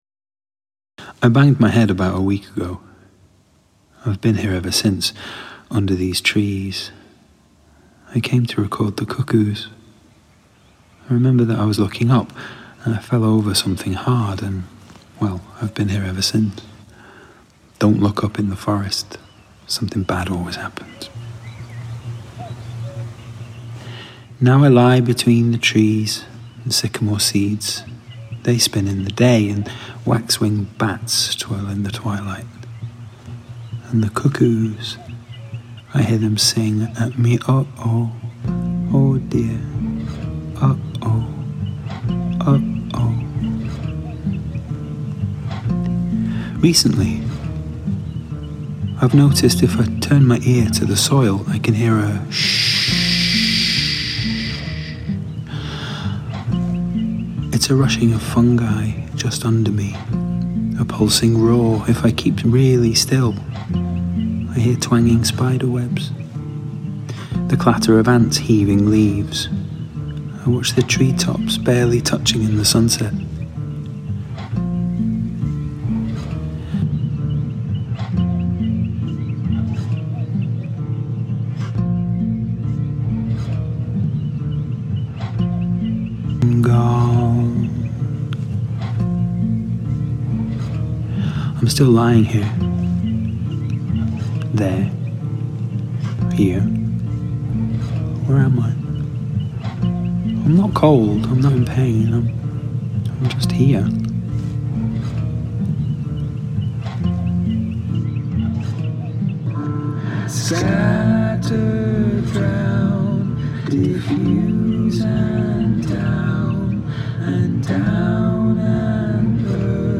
Cuckoos in Tomintoul, Scotland reimagined